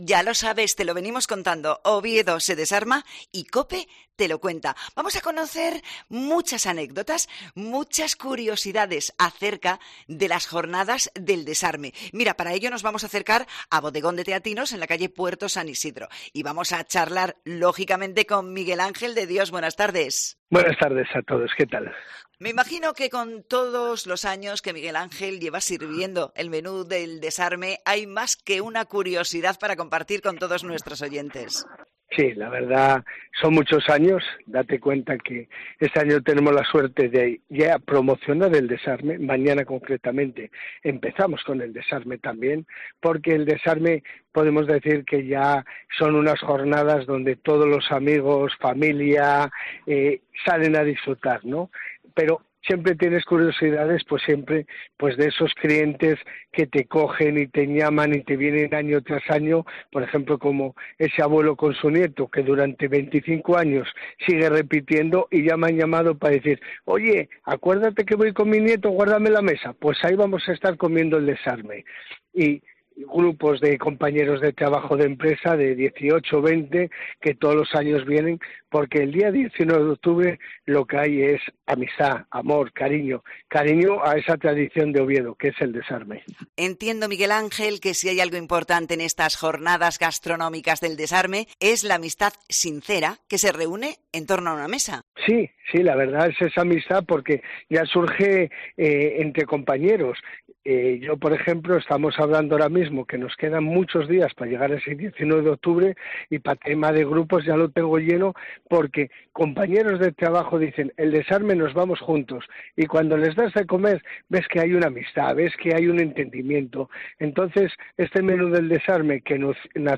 Jornadas del Desarme: entrevista